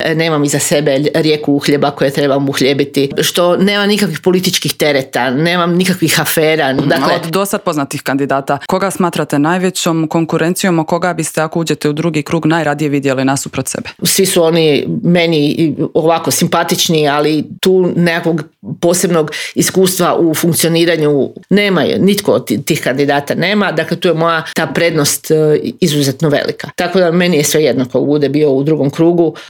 ZAGREB - Nezavisna kandidatkinja za zagrebačku gradonačelnicu Vesna Škare-Ožbolt u razgovoru za Media servis predstavila je svoj plan i program za Grad Zagreb.